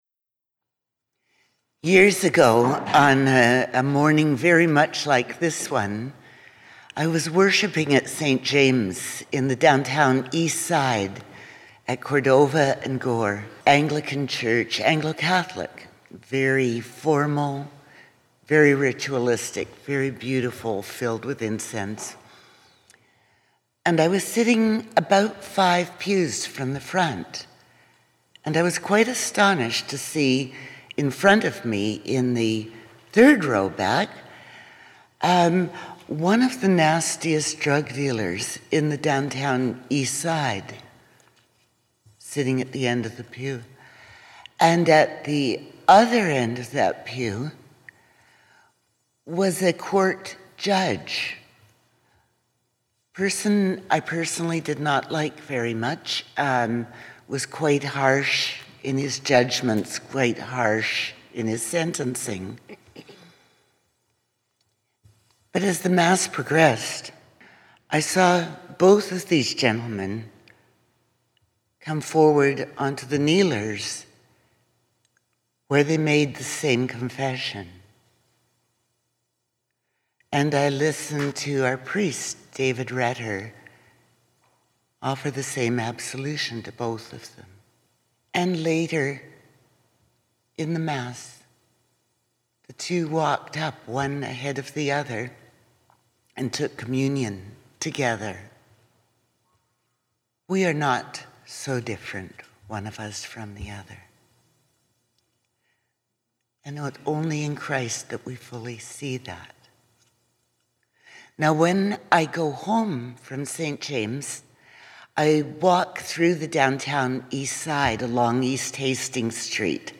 Twenty-first Sunday after Pentecost 2018